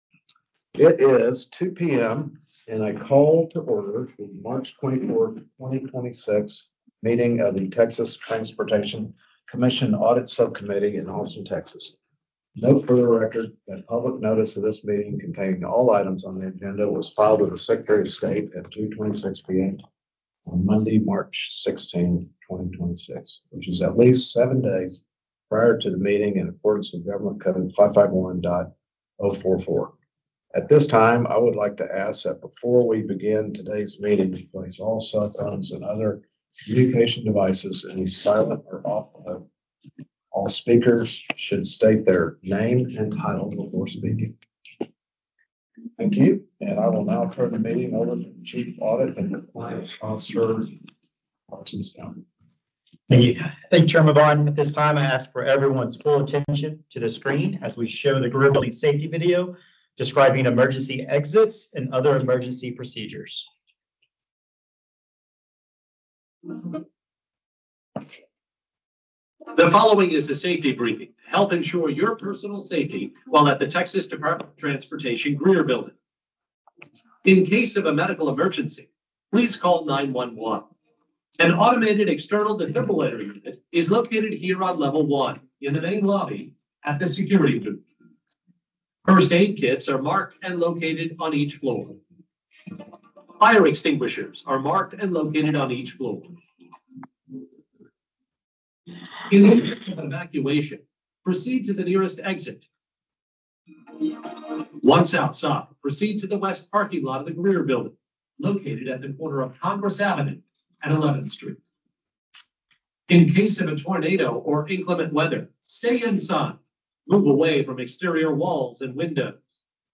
El Subcomité de Auditoría de la comisión se reúne trimestralmente. Todas las reuniones están abiertas al público y se llevan a cabo en el edificio Dewitt C. Greer, 125 E. 11th St., Austin, Texas, a menos que se indique lo contrario.